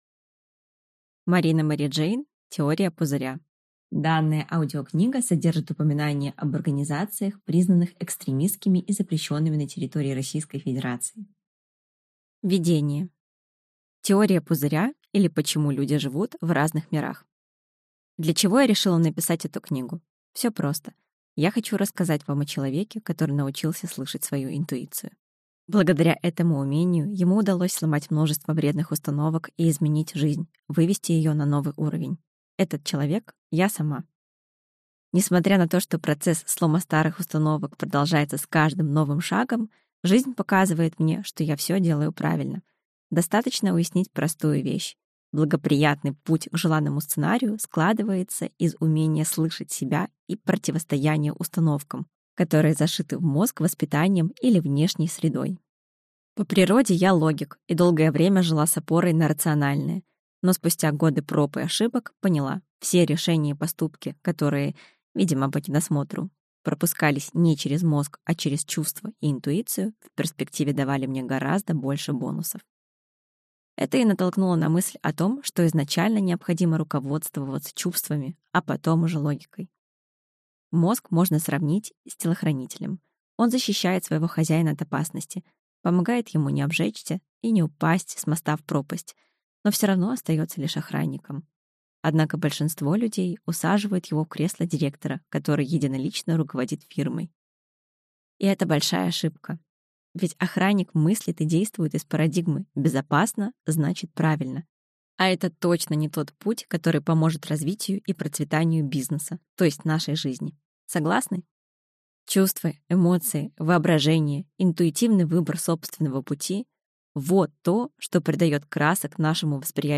Аудиокнига Теория пузыря. Перепрограммируй себя на гармонию | Библиотека аудиокниг